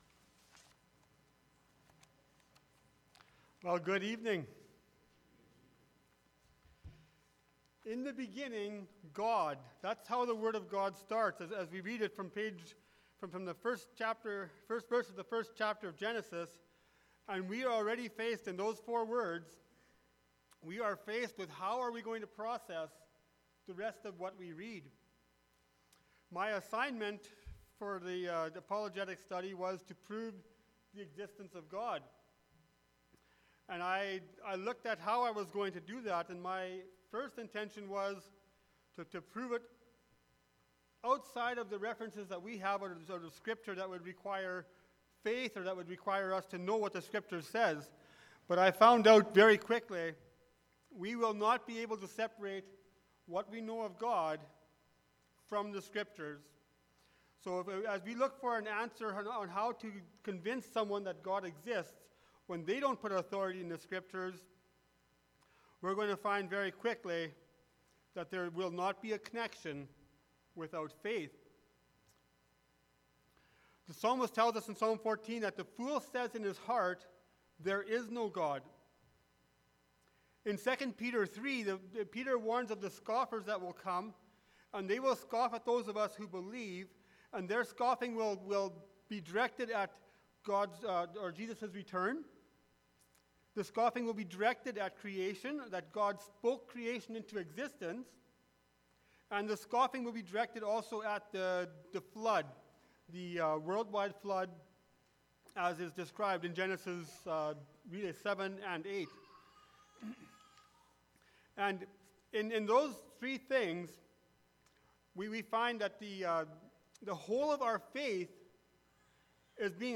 Church Bible Study – Apologetics